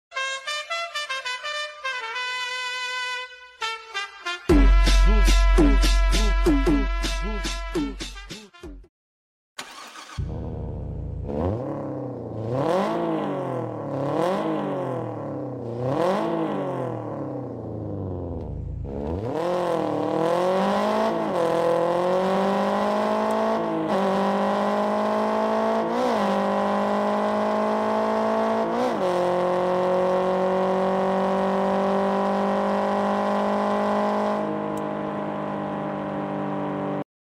أفض اصوات سيارات بدون تعديل sound effects free download